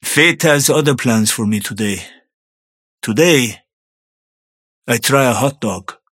Mirage voice line - Fate has other plans for me today.